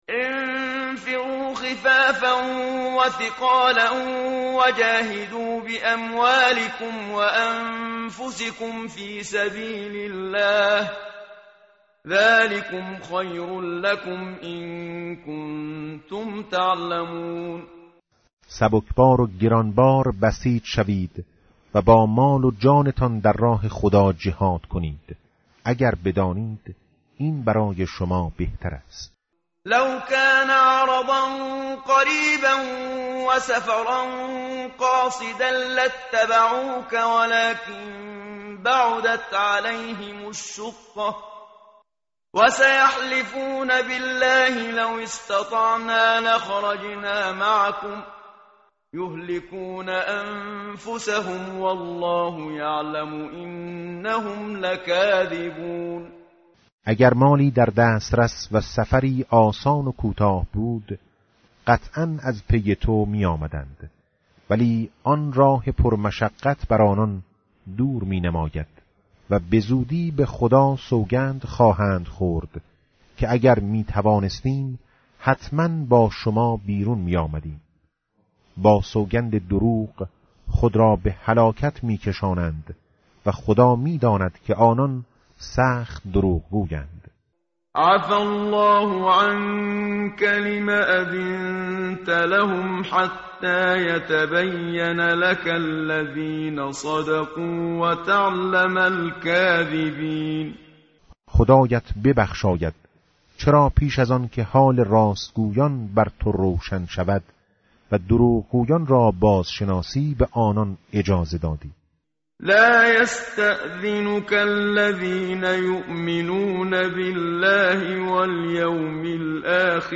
متن قرآن همراه باتلاوت قرآن و ترجمه
tartil_menshavi va tarjome_Page_194.mp3